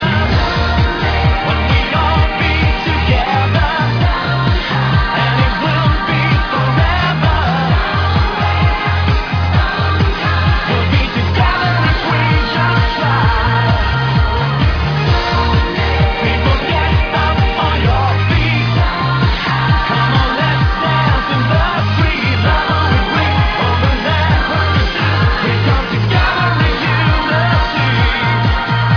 cheerful